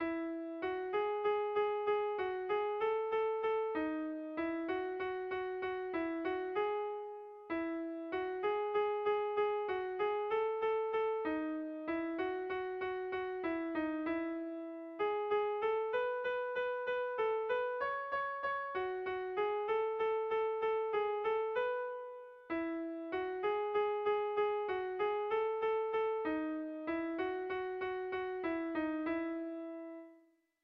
Zortziko handia (hg) / Lau puntuko handia (ip)
AABA